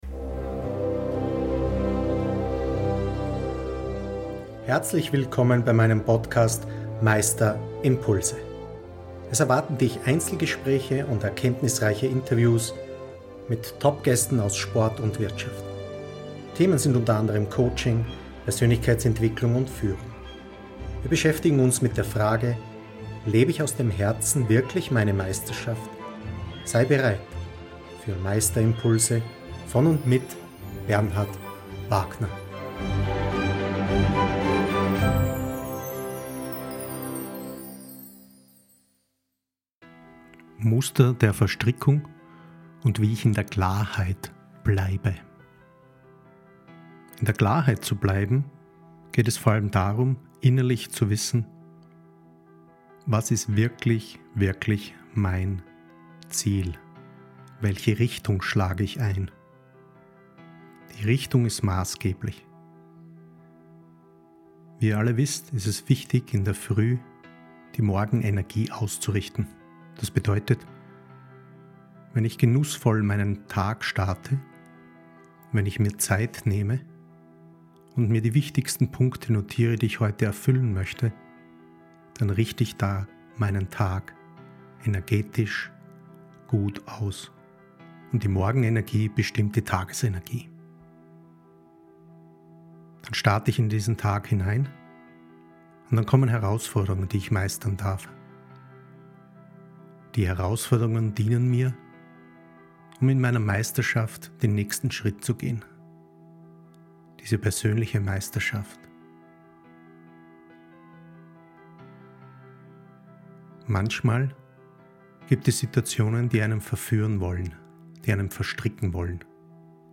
Aus Leistungssport, Karriere und persönlicher Meisterschaft – ruhig, reflektiert und praxisnah. Diese Episode unterstützt Sie dabei, äußere Verlockungen, Machtspiele und Abhängigkeiten zu erkennen und Ihren Weg bewusst, frei und selbstbestimmt zu wählen.